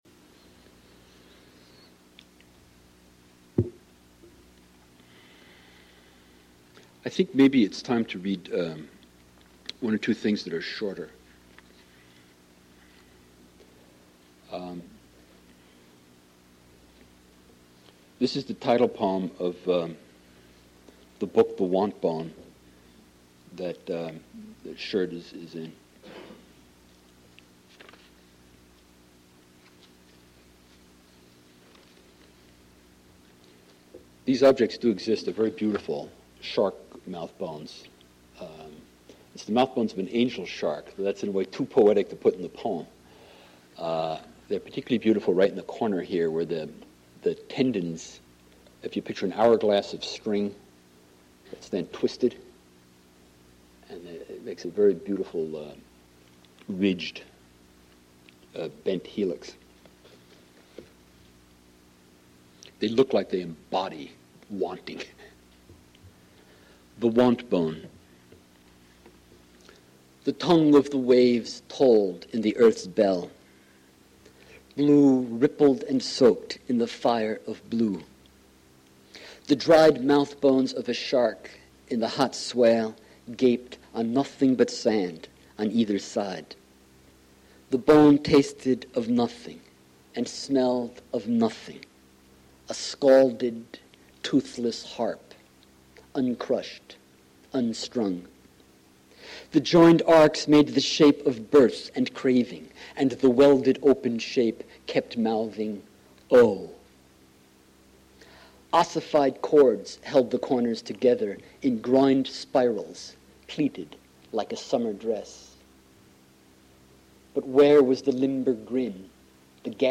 Spring Reading Series
Modern Languages Auditorium